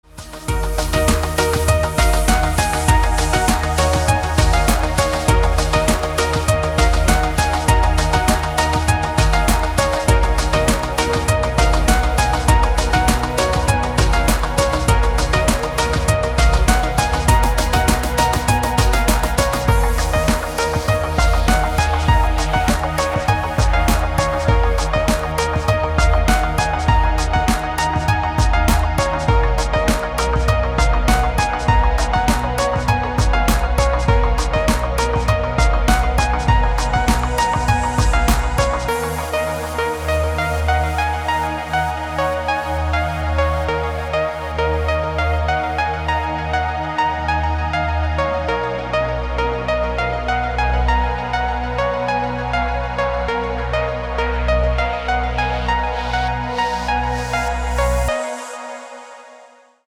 • Качество: 256, Stereo
электронная музыка
спокойные
без слов
красивая мелодия
Electronica
chillout
Downtempo
клавишные